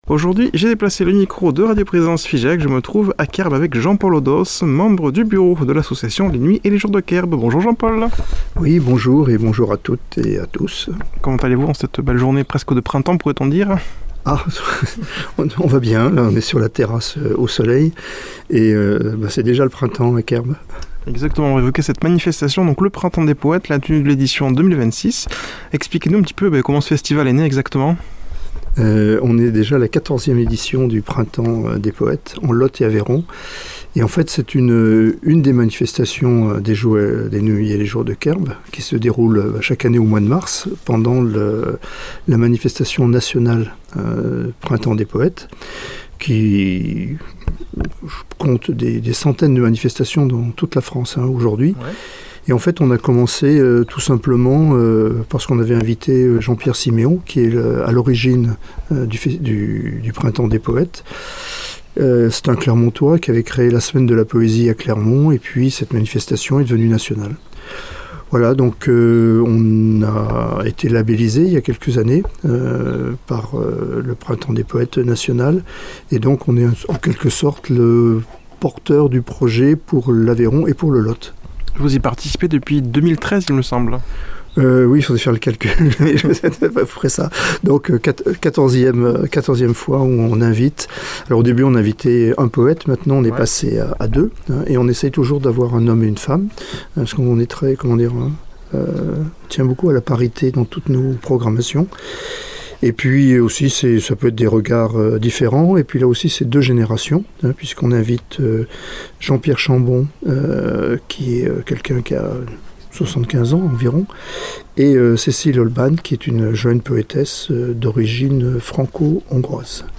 a déplacé le micro de Radio Présence Figeac, il se trouve à Querbes